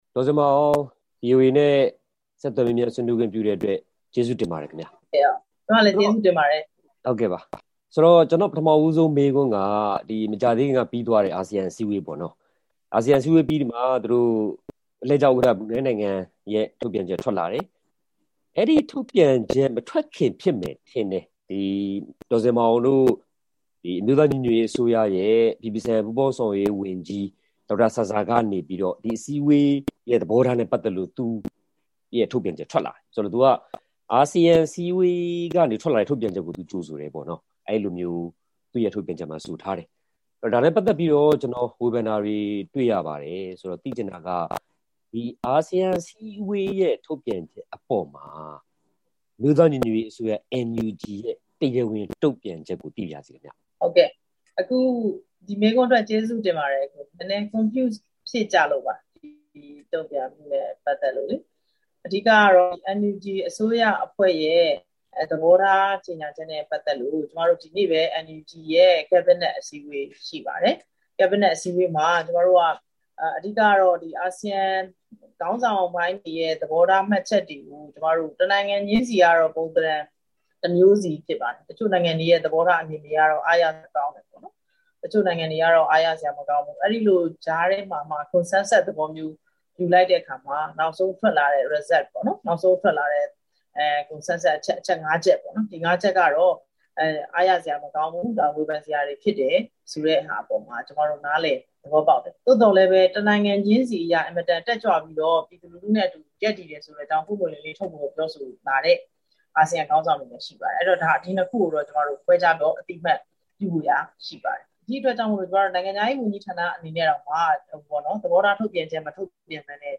NUG နိုင်ငံခြားရေးဝန်ကြီး ဒေါ်ဇင်မာအောင်နဲ့ ဆက်သွယ်မေးမြန်းချက်